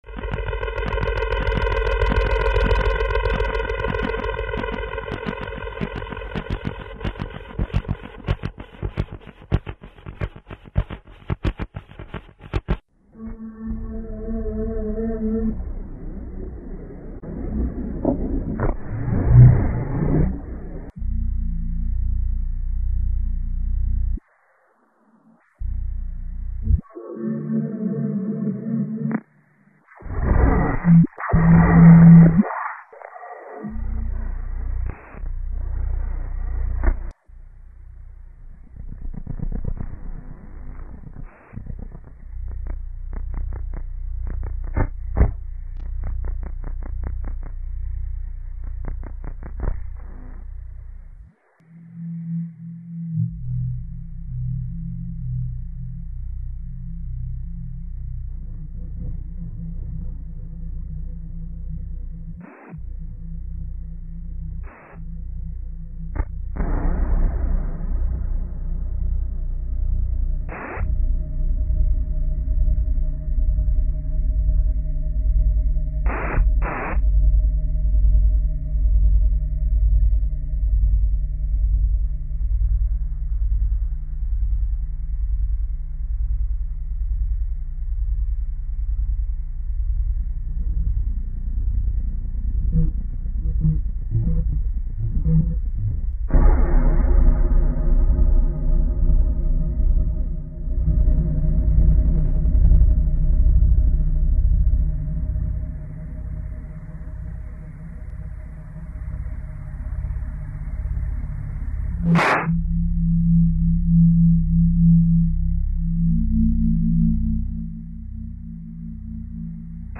For example, you can hear recordings of French economist Jacques Attali talking in London as the English composer listens to the cityscapes of Paris.